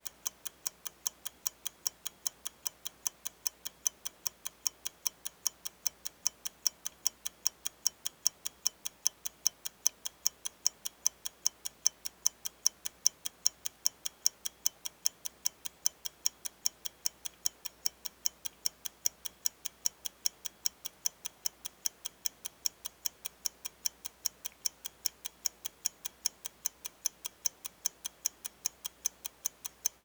秒表.wav